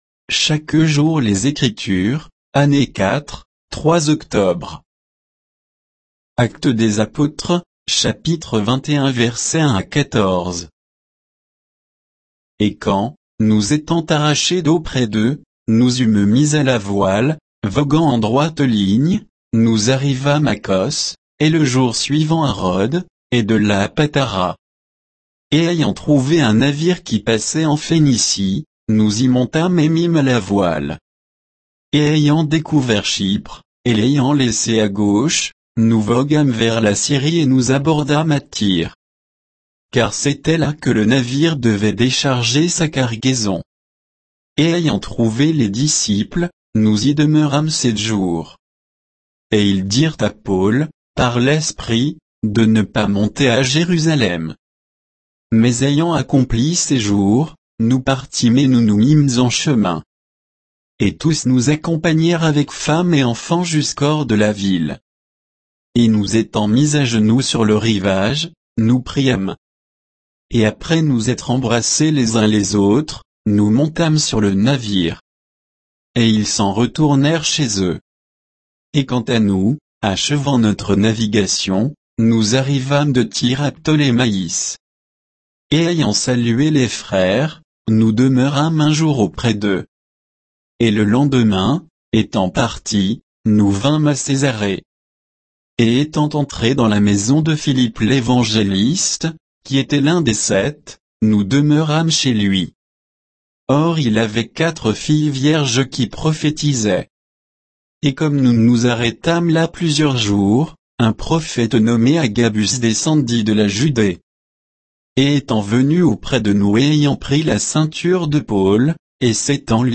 Méditation quoditienne de Chaque jour les Écritures sur Actes 21